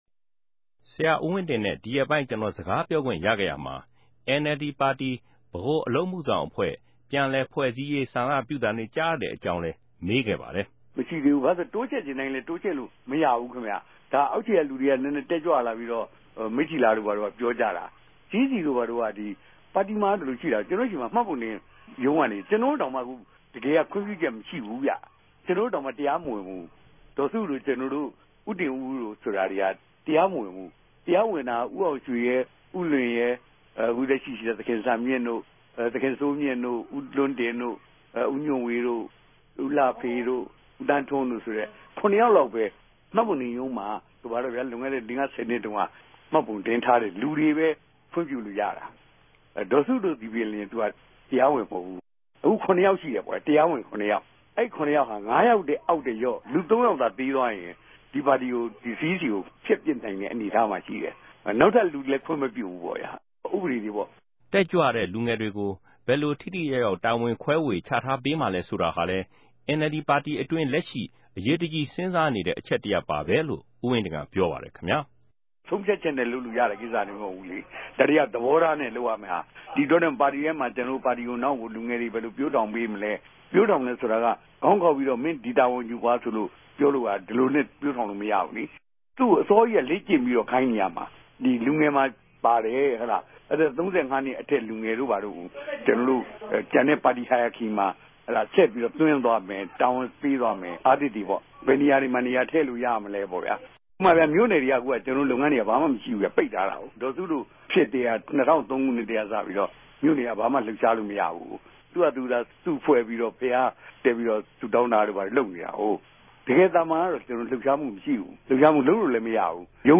NLD ၊ ဗဟိုအလုပ်မြဆောင်အဖြဲႚကို ူပင်ဆင်ဖိုႛ ူဖစိံိုင်ပၝ့မလား၊ ၂၀၁၀ ေ႟ြးကောက်ပြဲ ကို NLD လက်ခံိံိုင်ဖိုႛအတြက် အေူခခံလိုအပ်ခဵက်တေကြ ဘာတေလြဲ၊ လူငယ်တေကြို တာဝန်တြေ ဘယ်လိုခြဲဝေပေးမြာလဲစသူဖင့် မေးူမန်းဆြေးေိံြးထားပၝတယ်။